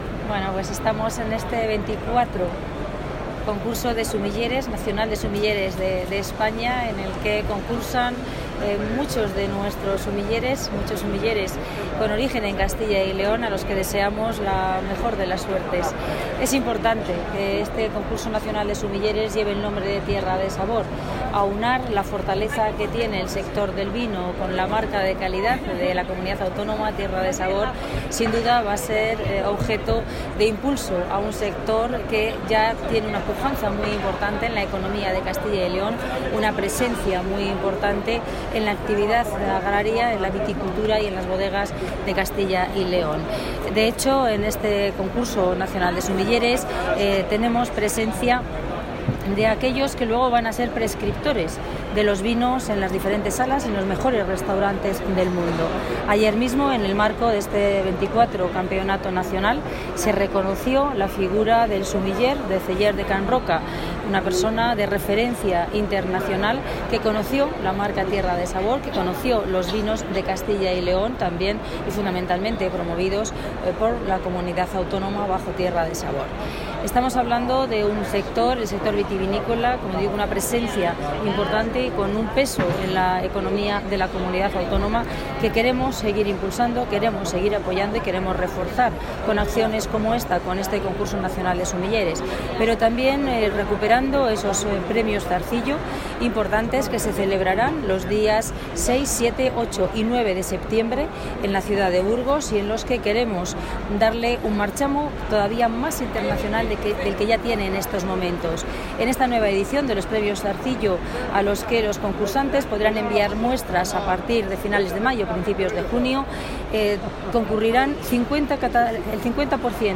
Material audiovisual de la inauguración del Campeonato de España de Sumilleres Tierra de Sabor 2018
Declaraciones de la consejera de Agricultura y Ganadería.